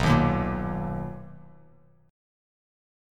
BM7 Chord
Listen to BM7 strummed